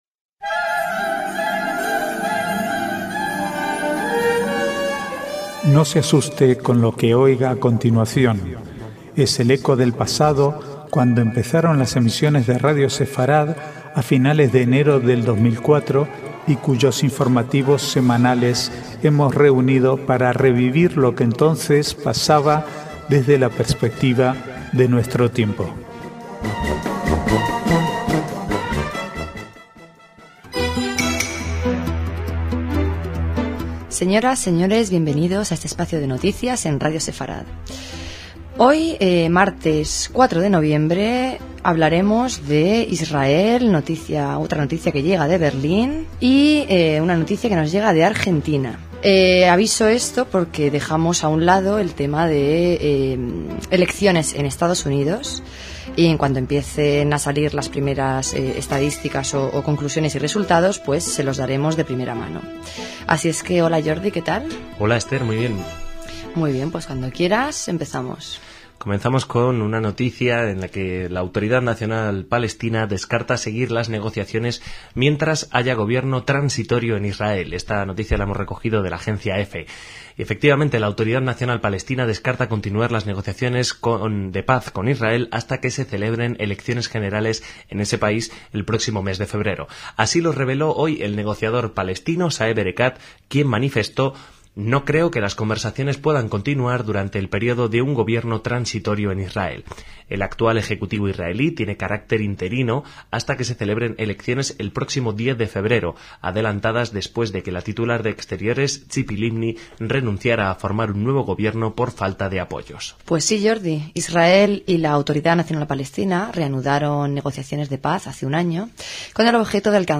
Archivo de noticias del 4 al 6/11/2008